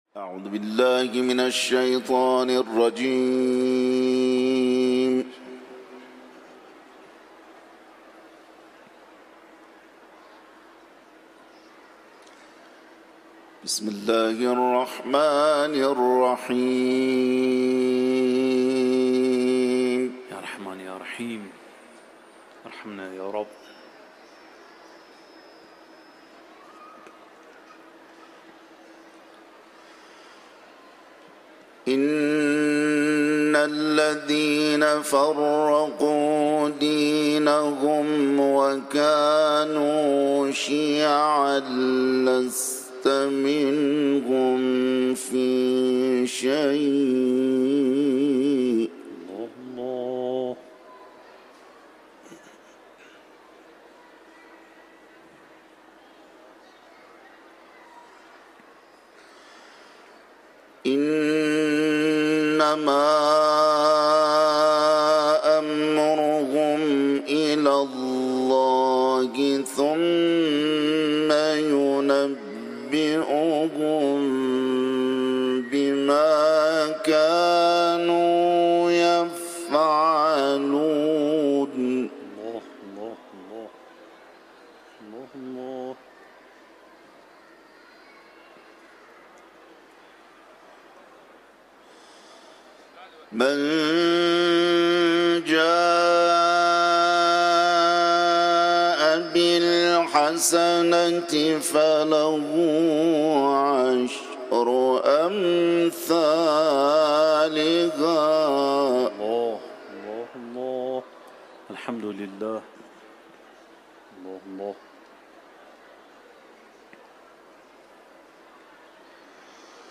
Kur’an tilaveti